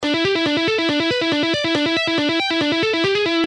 Pentatonic-Scale-Guitar-Licks-1.mp3